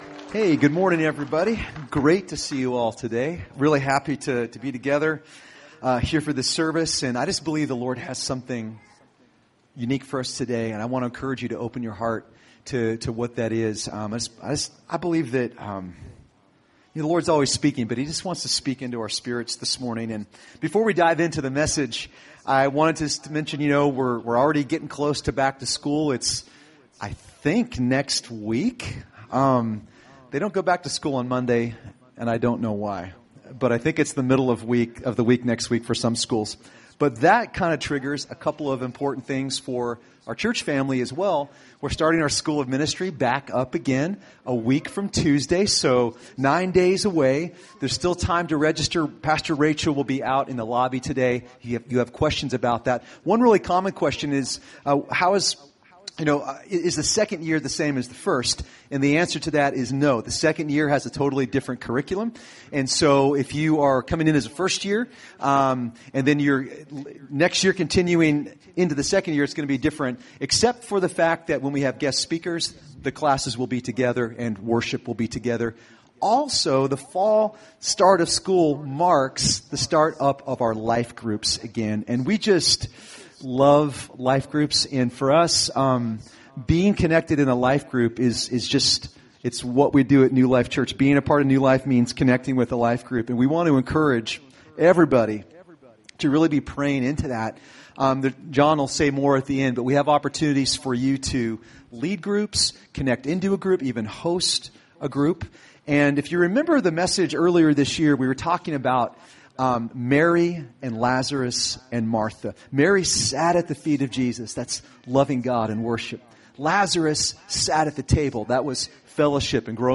Recorded at New Life Christian Center, Sunday, August 12, 2018 at 11 AM.